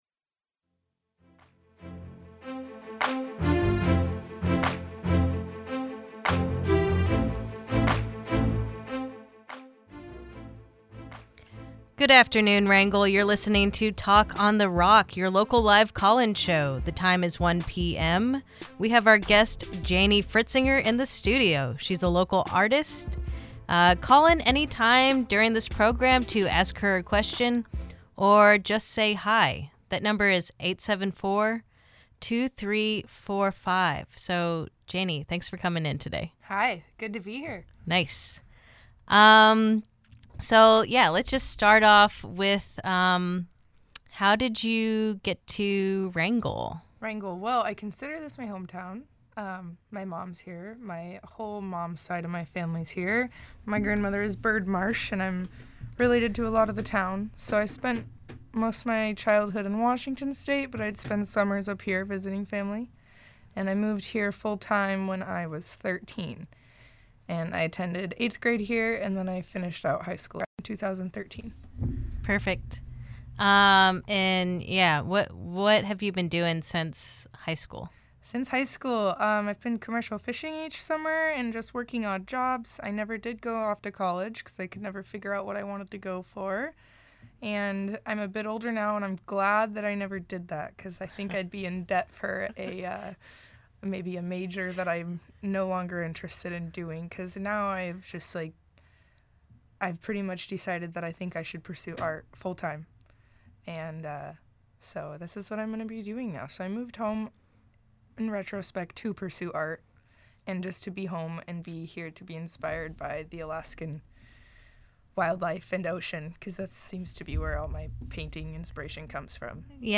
Talk on the Rock is Wrangell's live call-in show.
Talk on the Rock is KSTK’s live call-in show. Every Tuesday at 1pm we welcome a local guest to discuss events and topics of interest to our little island town.